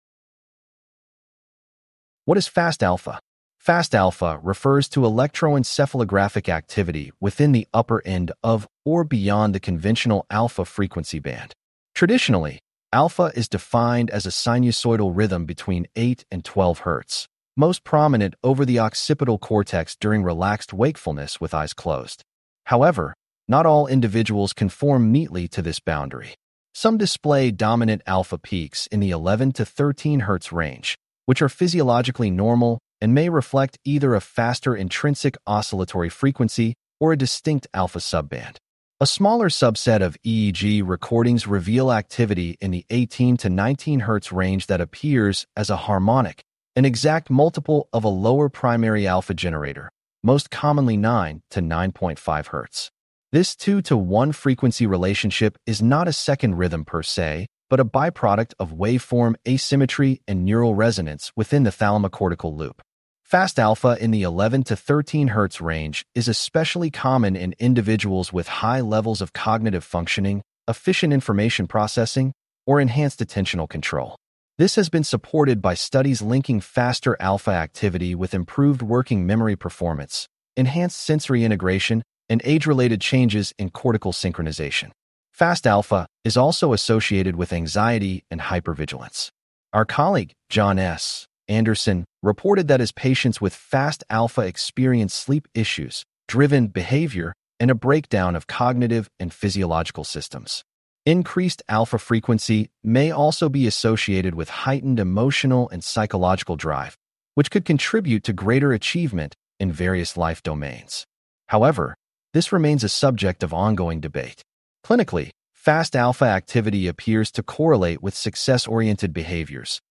CLICK TO HEAR THIS POST NARRATED Traditionally, alpha is defined as a sinusoidal rhythm between 8 and 12 Hz, most prominent over the occipital cortex during relaxed wakefulness with eyes closed.